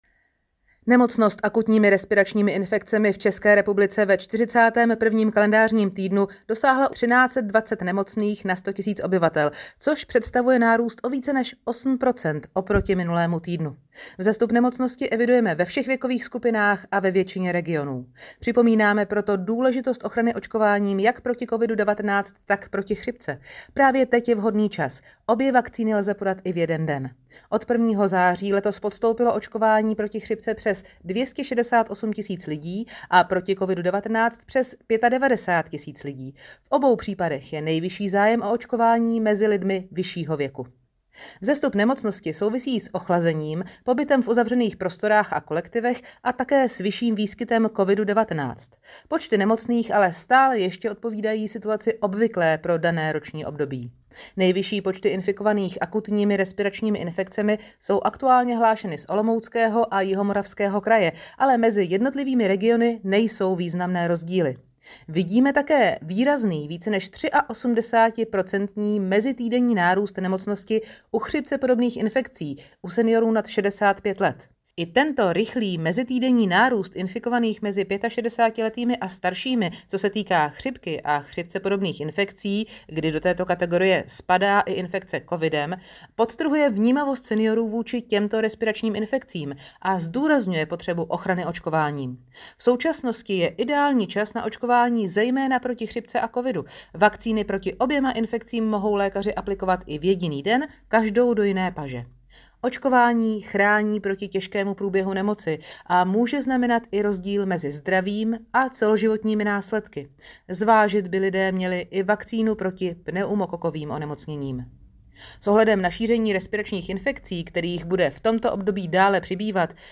Komentář k tiskové zprávě ARI/ILI 41. KT